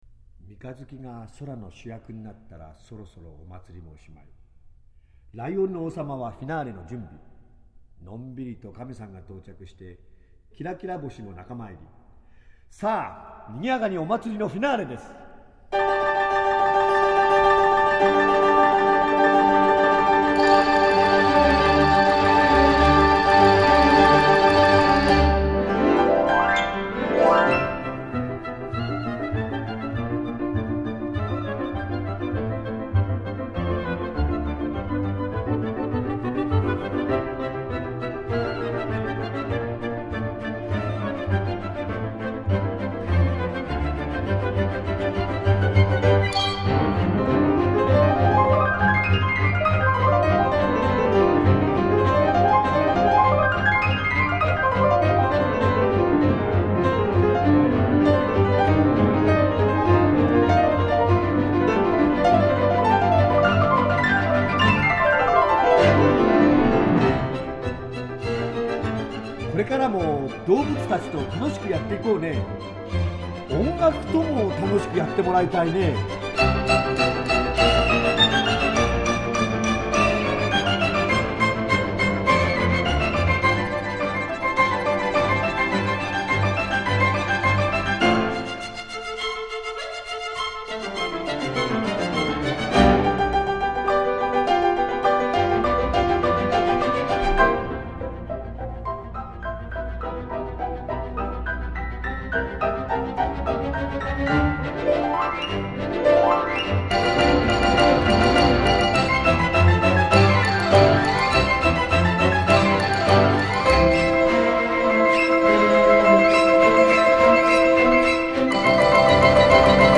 专辑风格：古典音乐、管弦乐
这是最适合青少年聆听的古典音乐，诙谐幽默，童趣盎然。